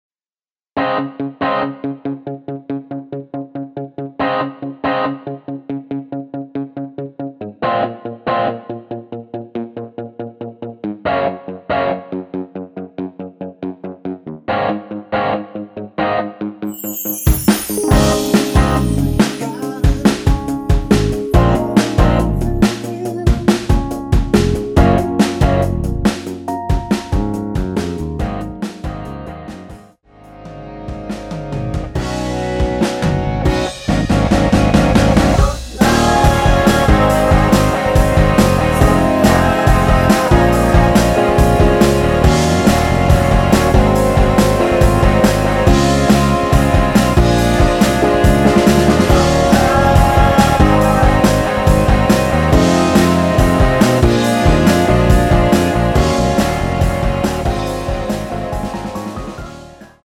원키에서(-1) 내린 코러스 포함된 MR 입니다.(미리듣기 참조)
Db
앞부분30초, 뒷부분30초씩 편집해서 올려 드리고 있습니다.
중간에 음이 끈어지고 다시 나오는 이유는